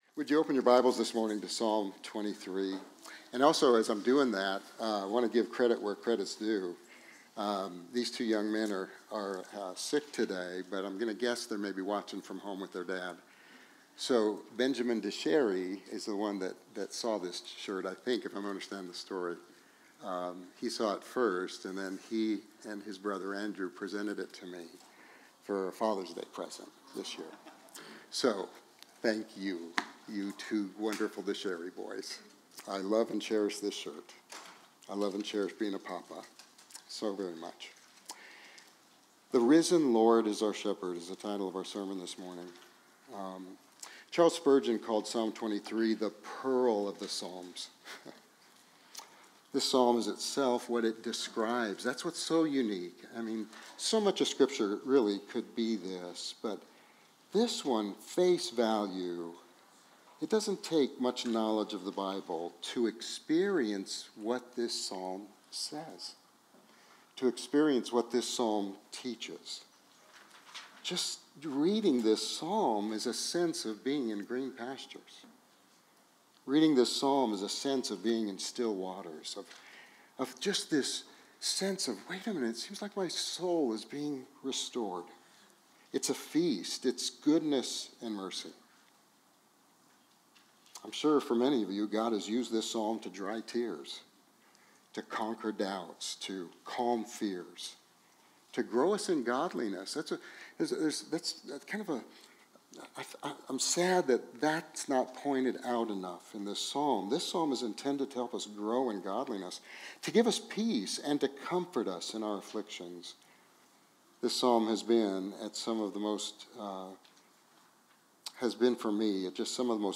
Biblical, Christ-centered Expositional Preaching from Sovereign Grace Church in Midland, TX